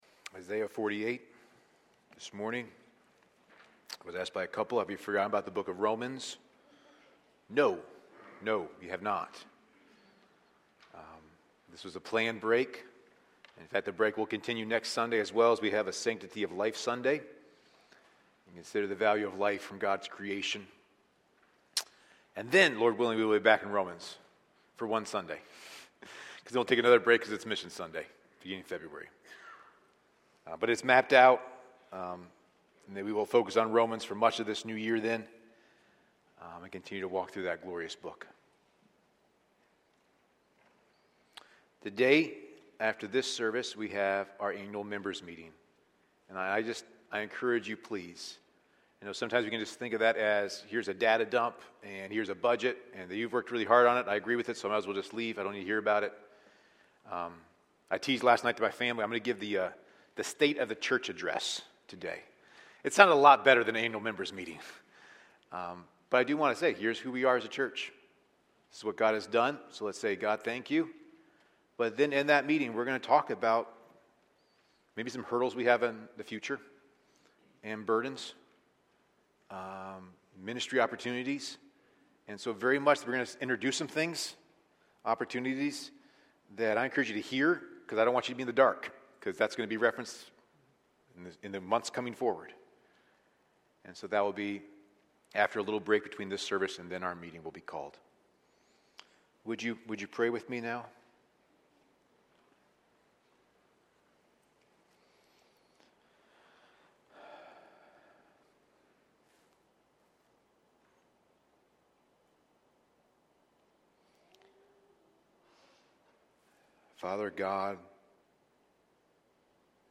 A sermon from the series "Individual Sermons."